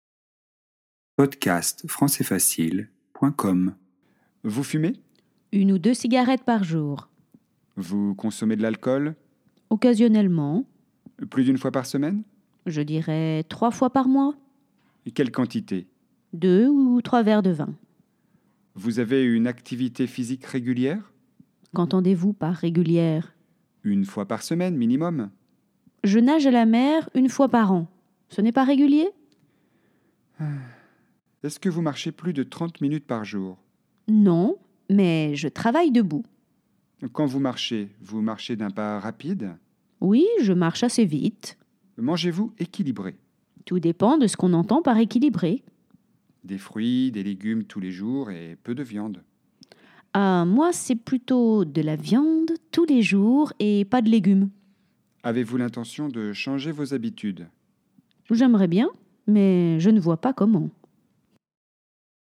Situation : un docteur pose des questions à sa patiente pour savoir si elle a une bonne hygiène de vie
🔷 DIALOGUE :